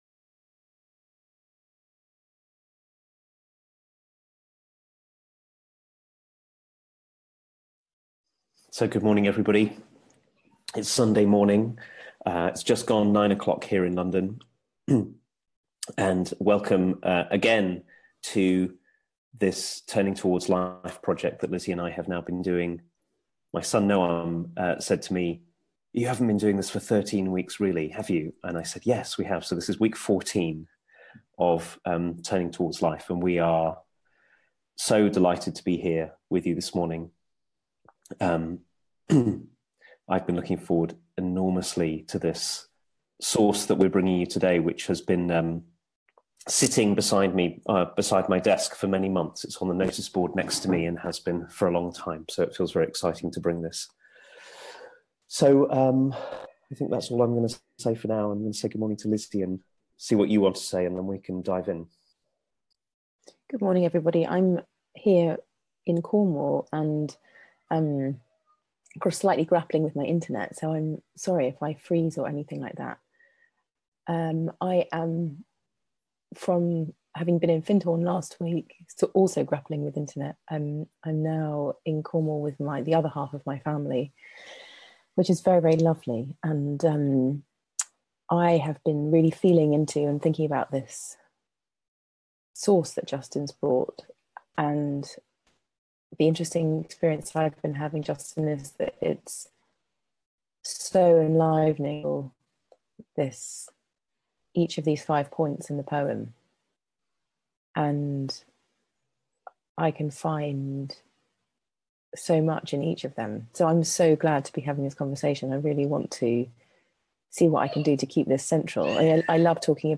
a weekly live 30 minute conversation